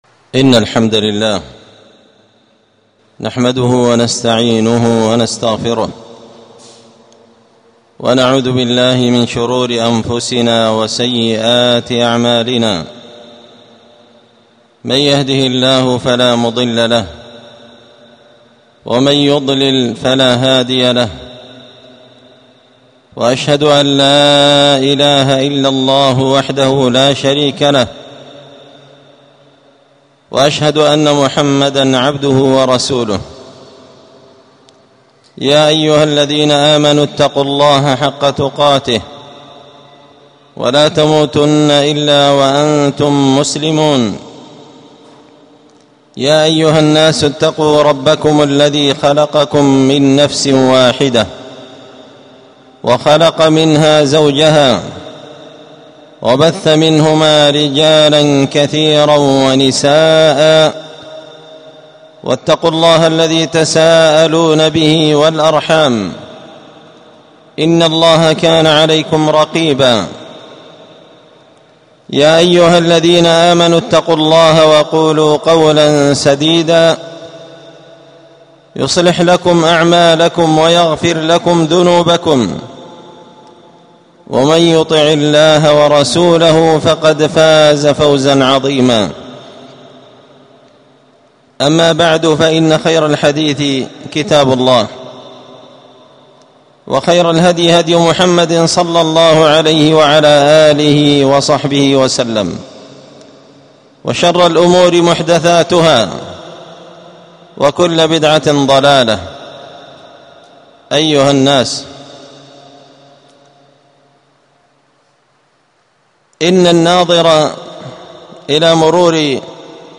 خطبة جمعة بعنوان
ألقيت هذه الخطبة بدار الحديث السلفية بمسجد الفرقان